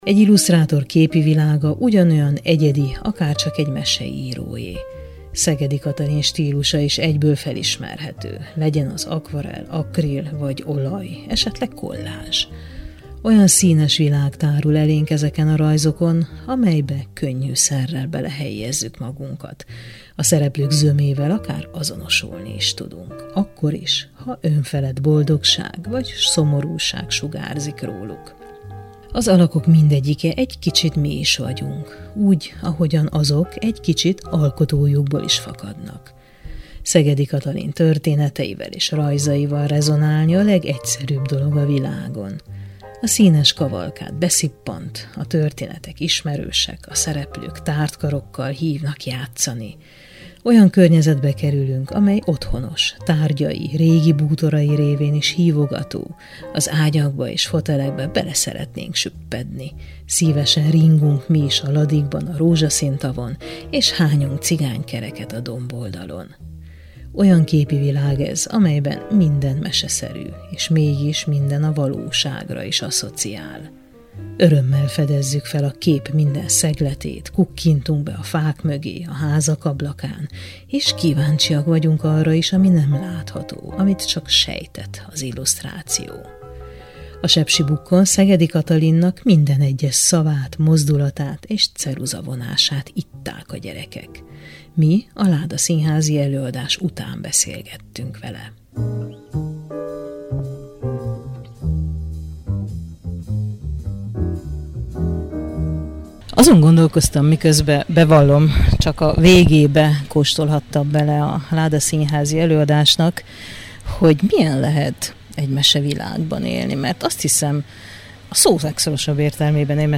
Mesevilágban élek – Beszélgetés
Mi a ládaszínházi előadás után beszélgettünk vele.